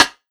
150JAMRIM1-L.wav